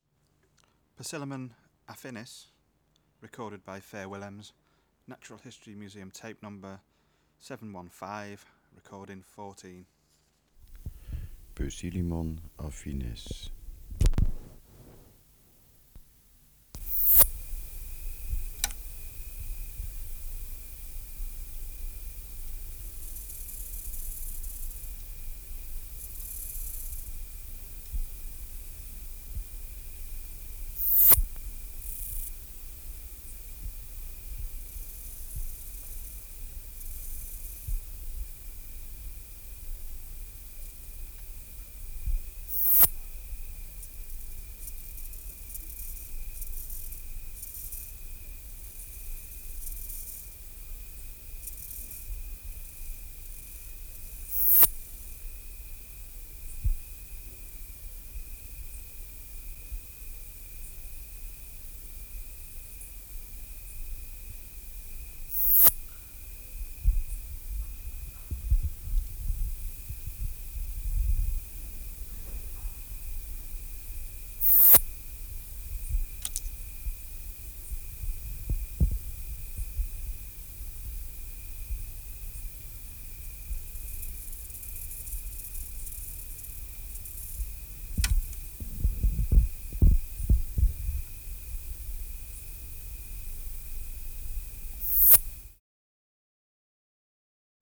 Species: Poecilimon (Poecilimon) affinis
Air Movement: Nil Extraneous Noise: Rhacocleis, Oecanthus & Chorthippus Substrate/Cage: In cage Biotic Factors / Experimental Conditions: Isolated male
Distance from Subject (cm): 8